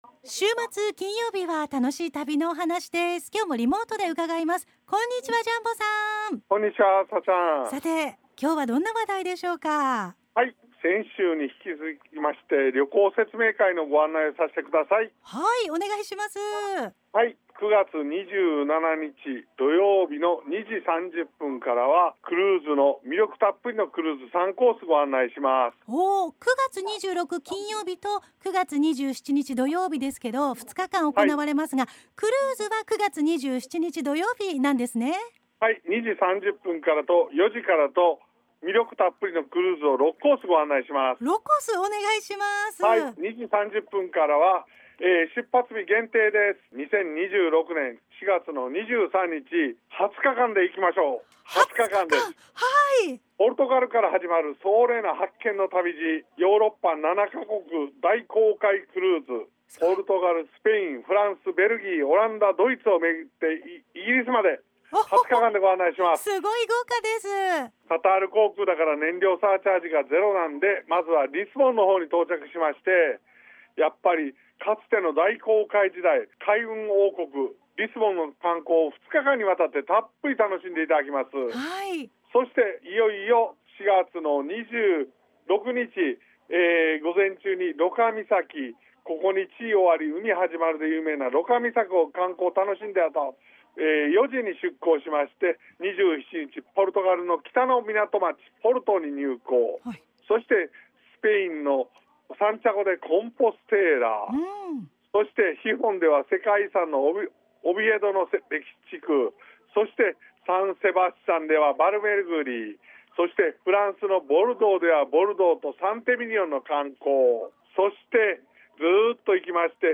★『（9/26・9/27）海外旅行説明会 Part.2』2025年9月12日(金)ラジオ放送